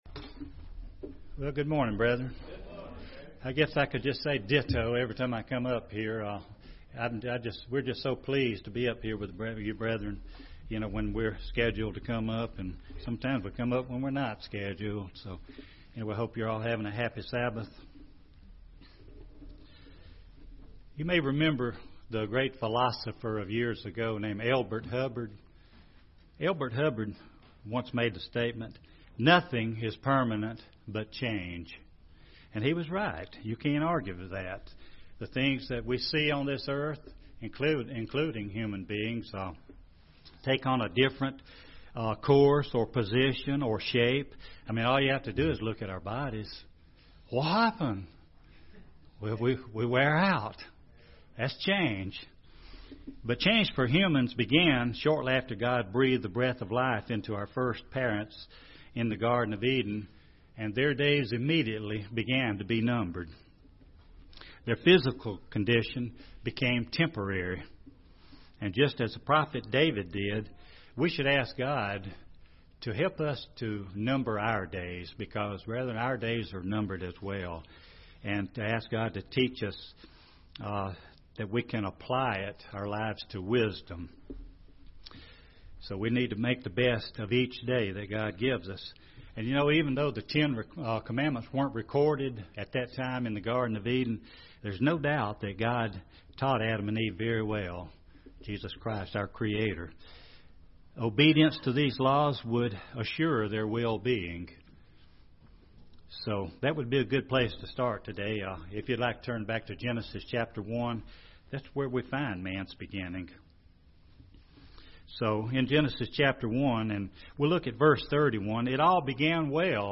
This sermon discusses many changes for the worse that are taking place in America.